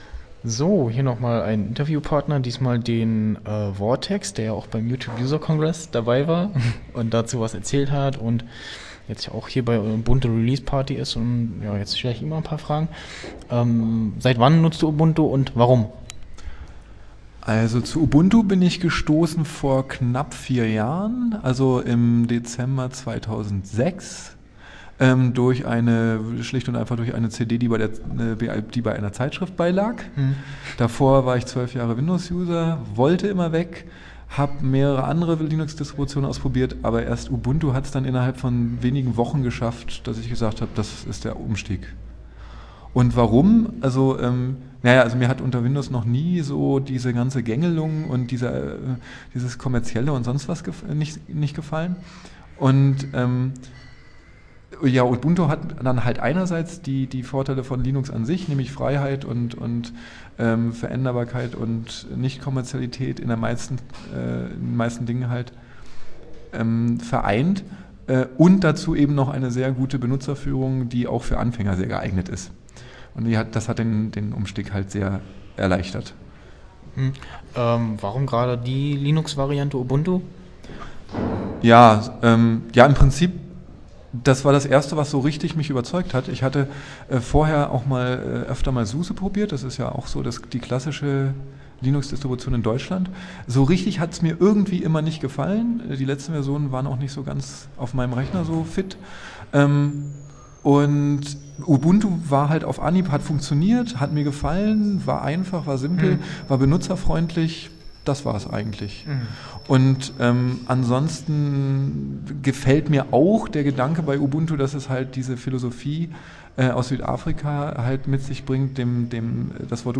Interview
bei der Ubuntu Release Party